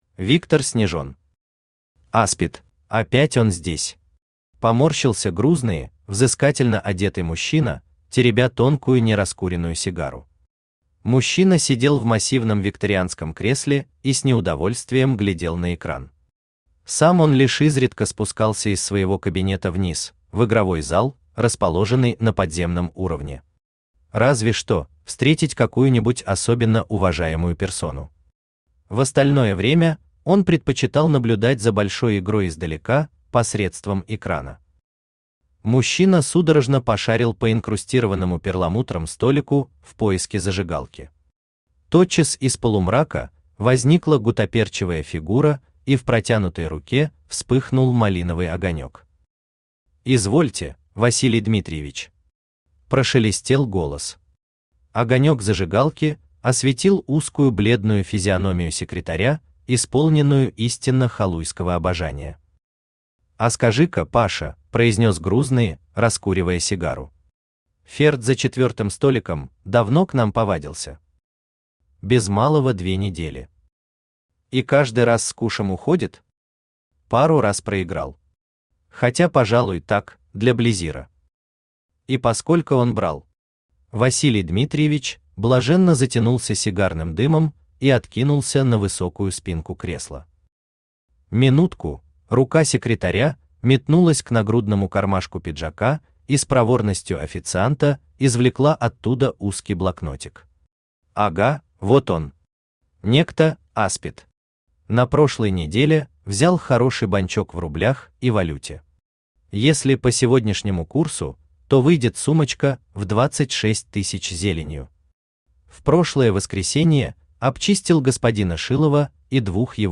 Аудиокнига Аспид | Библиотека аудиокниг
Aудиокнига Аспид Автор Виктор Снежен Читает аудиокнигу Авточтец ЛитРес.